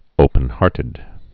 (ōpən-härtĭd)